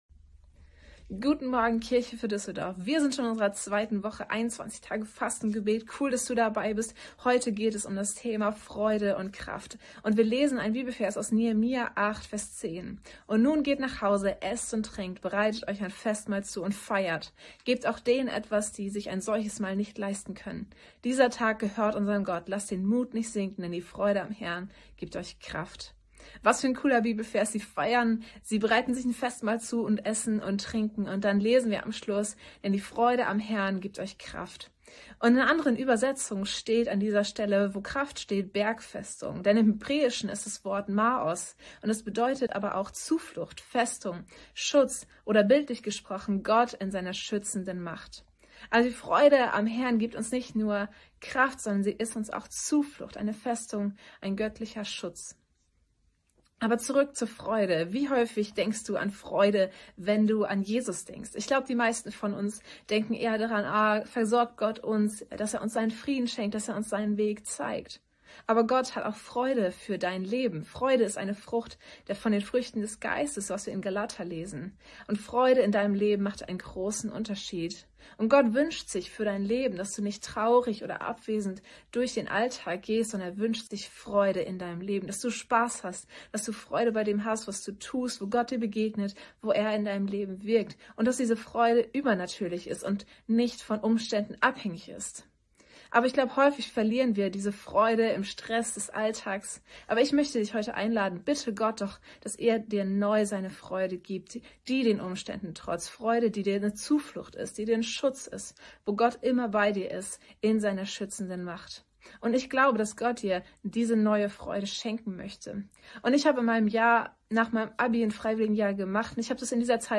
Andacht zu unseren 21 Tagen des Gebets